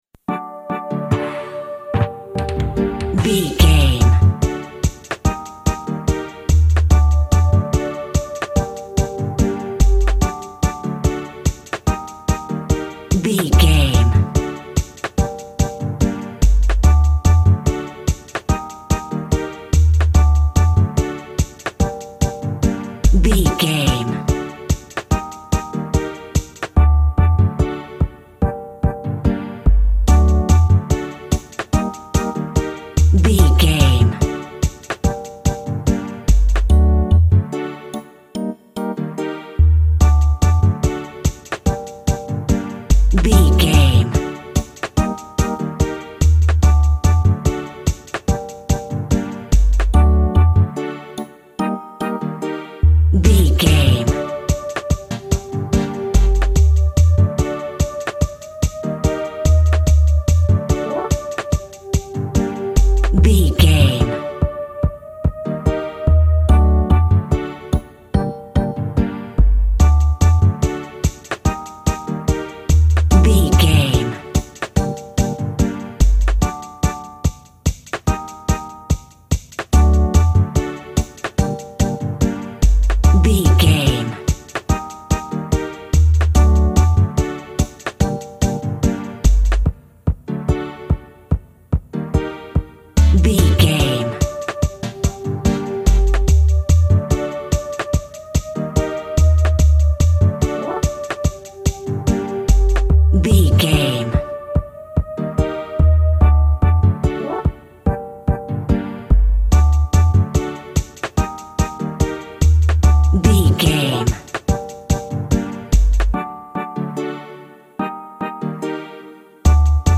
Also with small elements of Dub and Rasta music.
Uplifting
Ionian/Major
D
drums
bass
guitar
piano
brass
steel drum